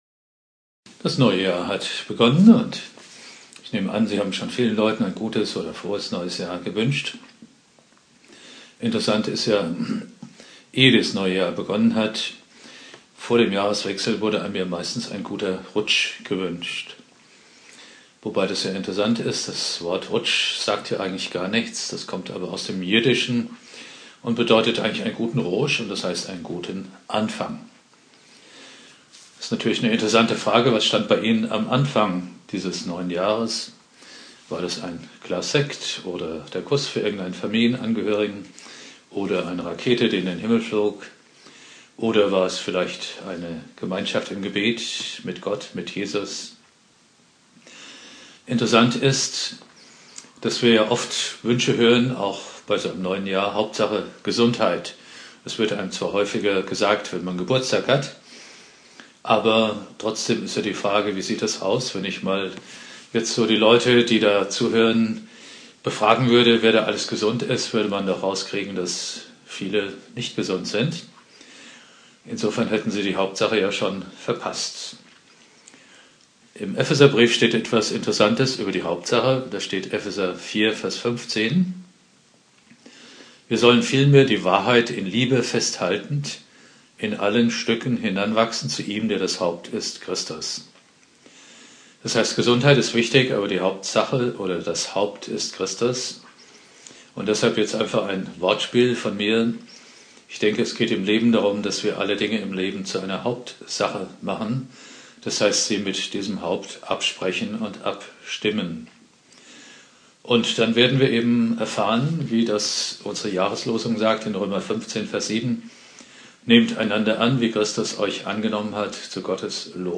Predigt
Neujahr Prediger